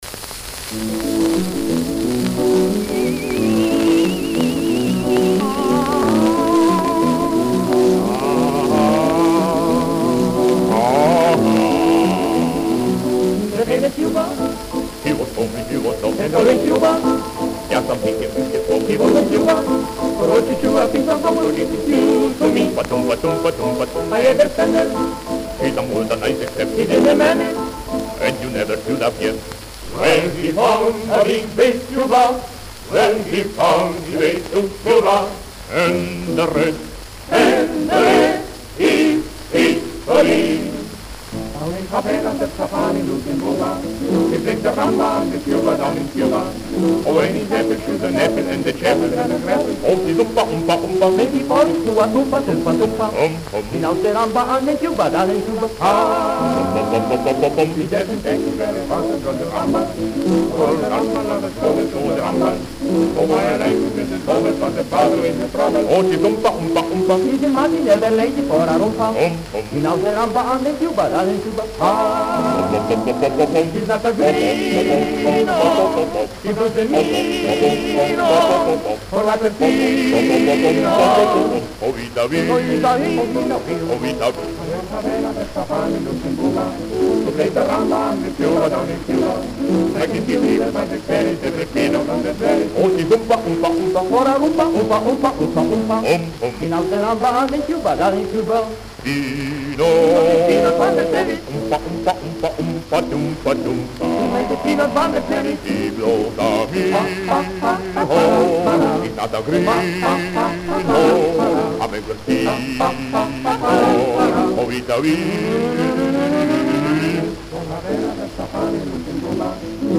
исп. вокальный джаз